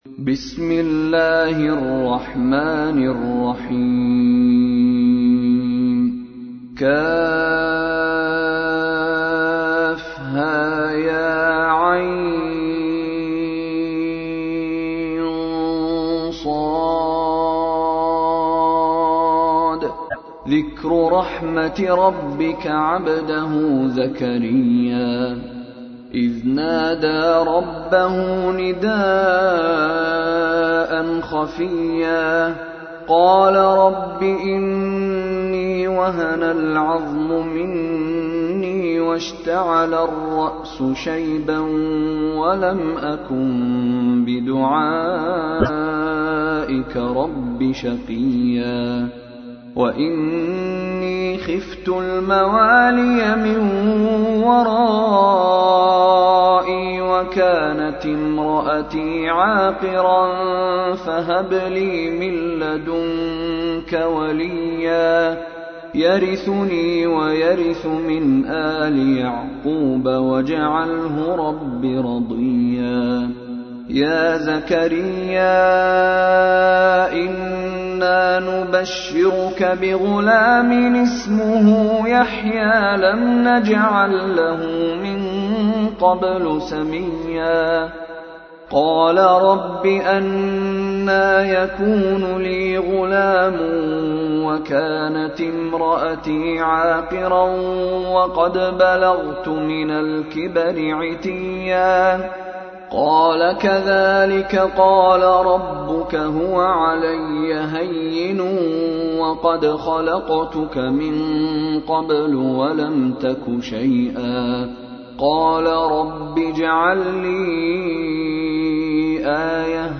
تحميل : 19. سورة مريم / القارئ مشاري راشد العفاسي / القرآن الكريم / موقع يا حسين